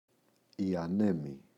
ανέμη, η [a’nemi]